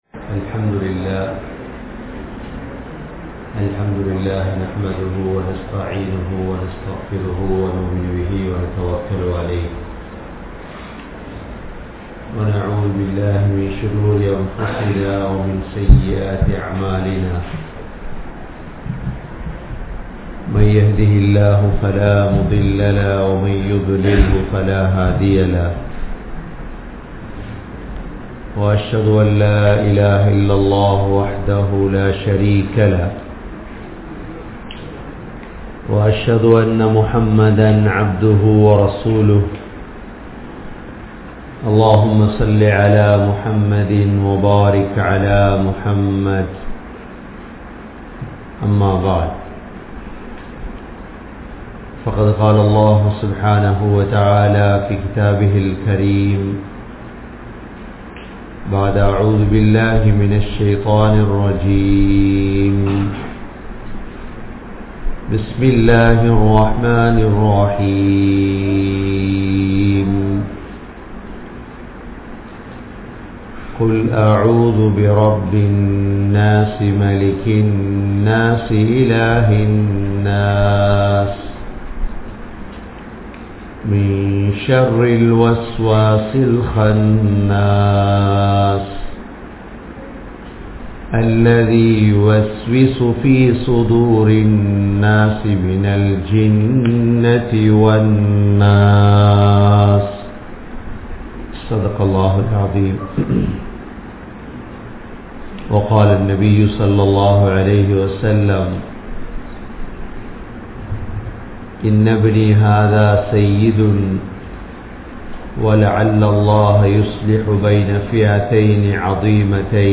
Hassan(Rali) | Audio Bayans | All Ceylon Muslim Youth Community | Addalaichenai
Canada, Toronto, Thaqwa Masjidh